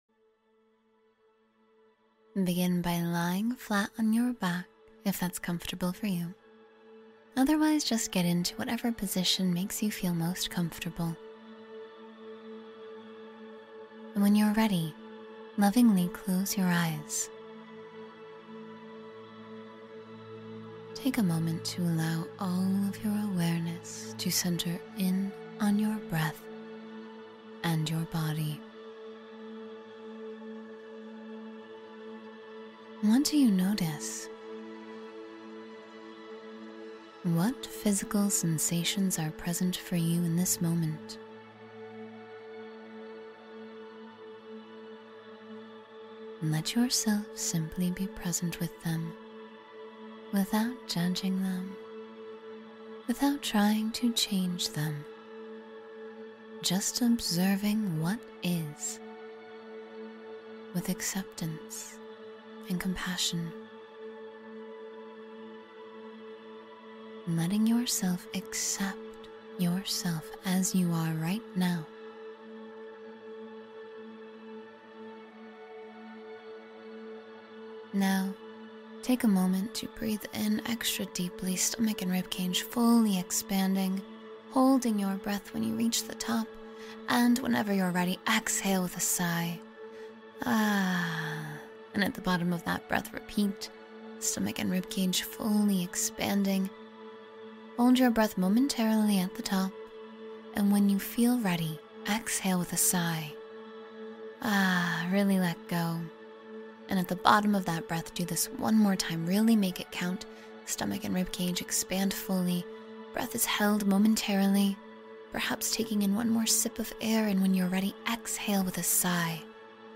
Float Through the Cosmos of Calm — 10-Minute Guided Meditation